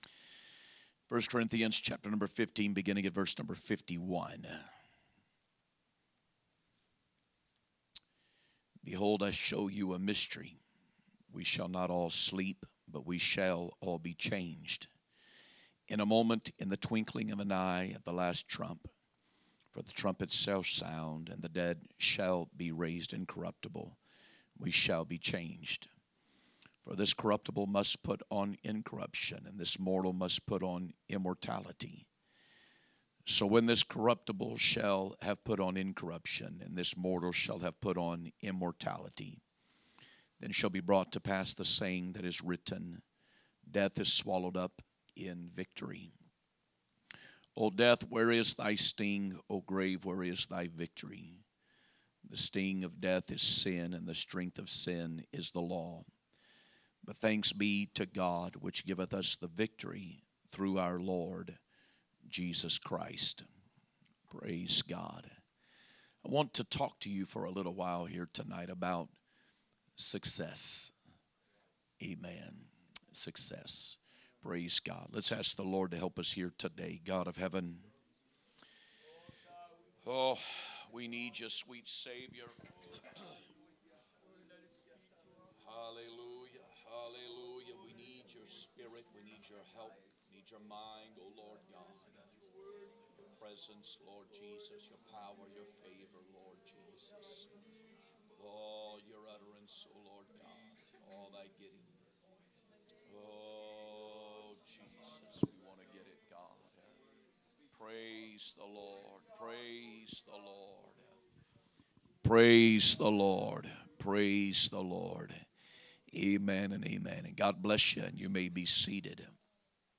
3/22/2026 Sunday Evening Service